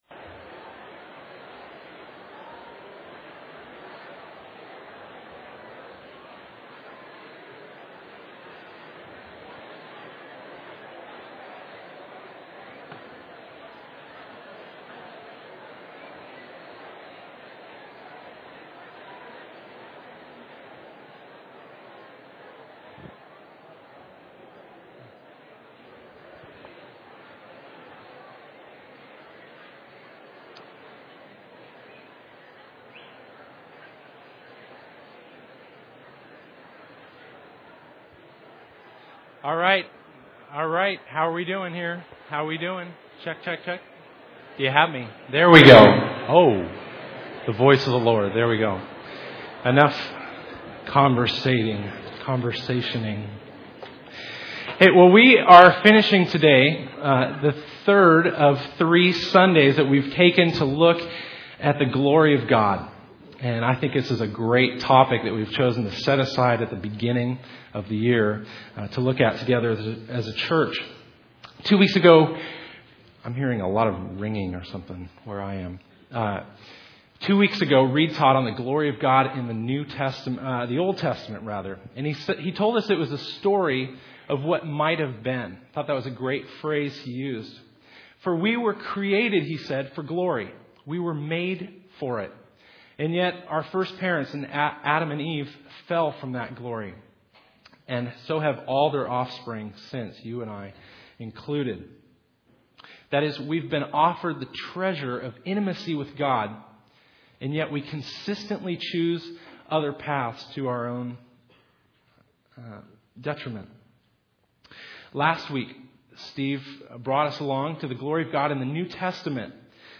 The Story of Gods Glory Service Type: Sunday The Story of God's Glory « The Story of Gods Glory